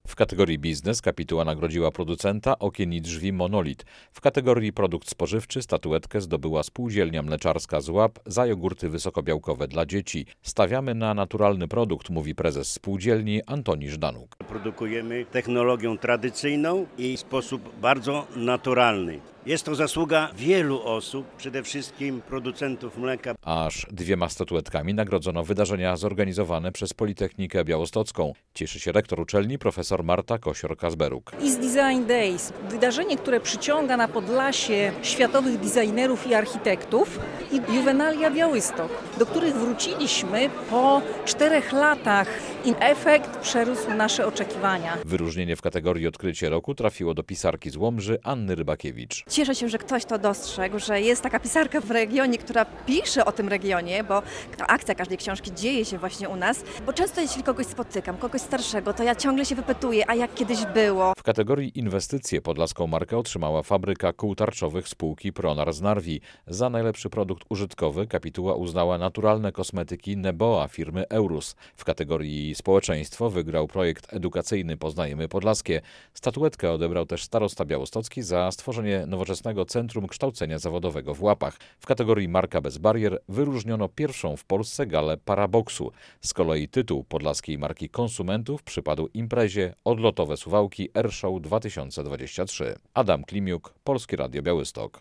Najwartościowsze produkty, najlepsze pomysły i najciekawsze wydarzenia - nagrodzone. Podczas gali w białostockiej Operze poznaliśmy laureatów dorocznego konkursu Podlaska Marka.
relacja